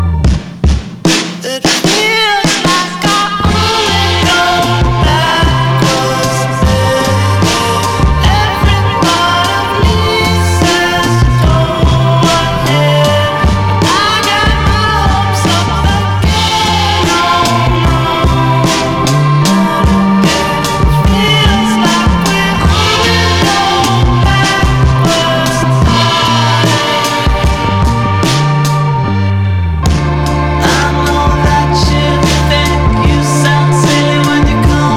Жанр: Рок / Альтернатива
Rock, Psychedelic, Alternative, Indie Rock